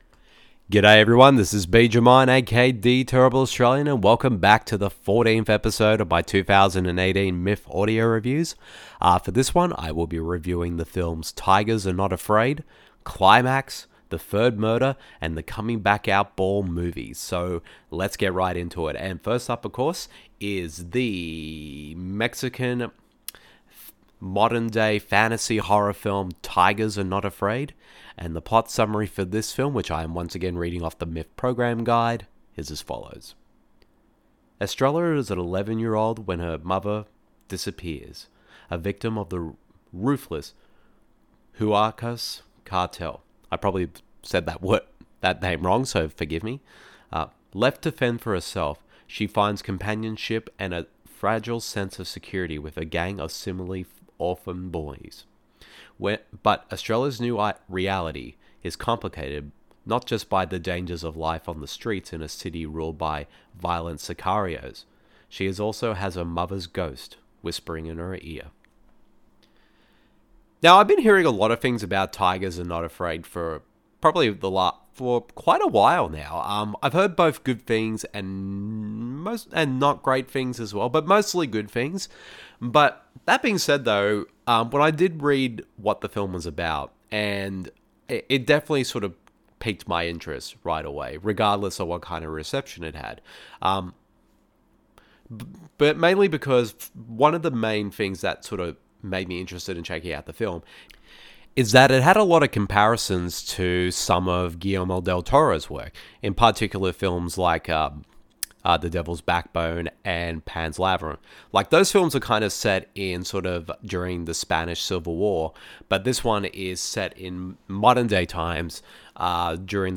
Welcome to the 14th edition of my 2018 Melbourne International Film Festival (a.k.a. MIFF) audio reviews. In this one, I share my thoughts on the Mexican modern horror/fantasy TIGERS ARE NOT AFRAID, controversial director Gaspar Noe’s latest film CLIMAX , director Hirokazu Kore-eda’s courtroom drama THE THIRD MURDER and the Aussie crowd pleasing documentary on LGBTQIA+ elders THE COMING BACK OUT BALL MOVIE.